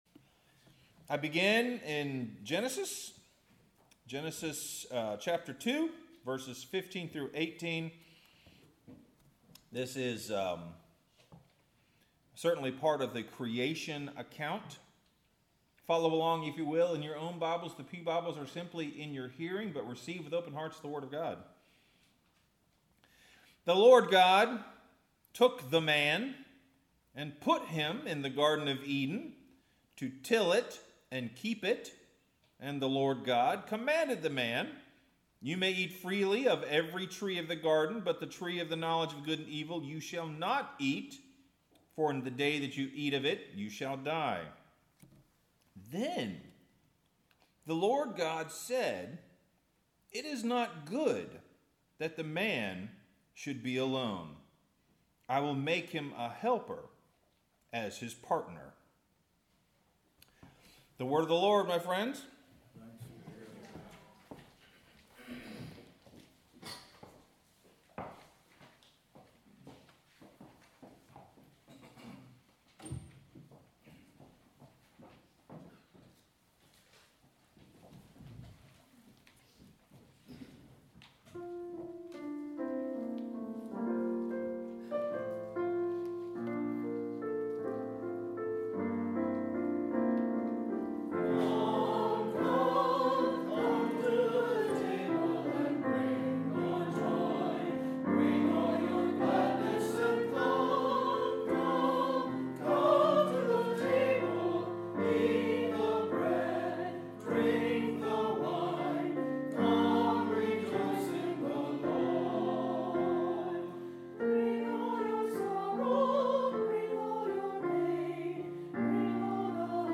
Sermon – Loose Connections and Staying Grounded